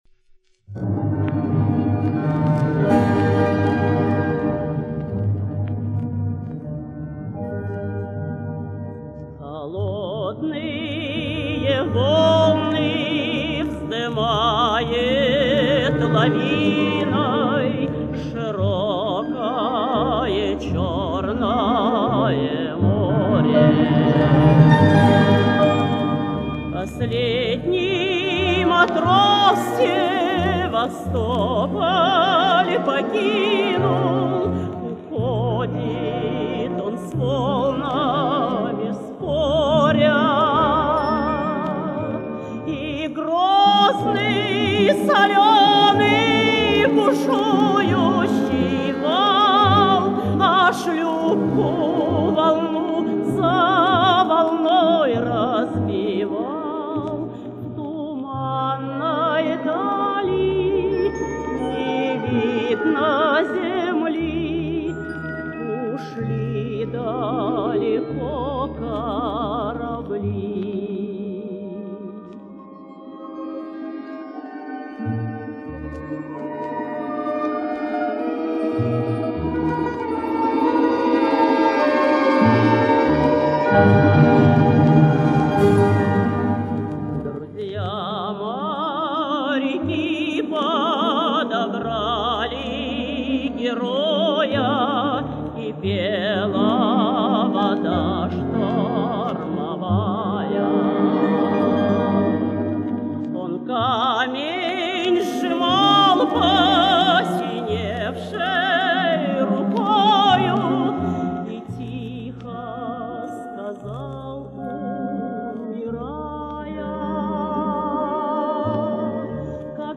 Перезапись с грампластинки